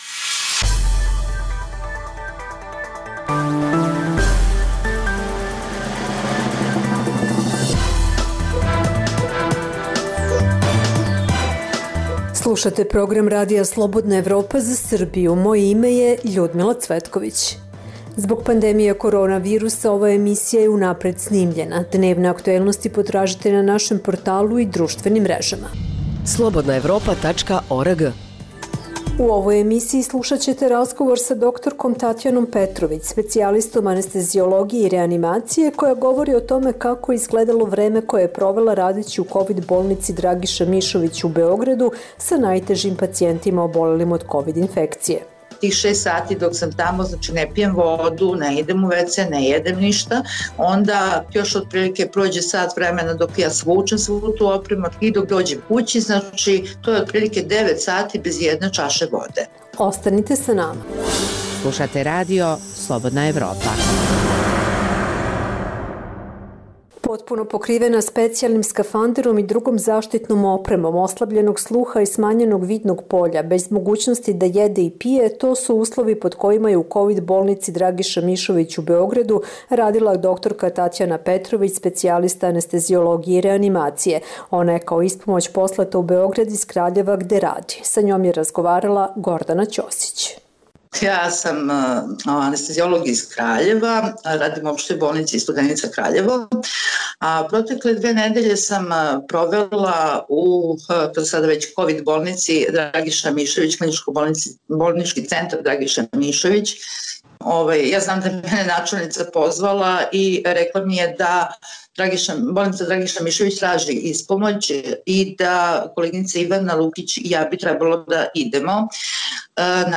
Ovo je program Radija Slobodna Evropa za Srbiju. Zbog pandemije korona virusa ova emisija je unapred snimljena.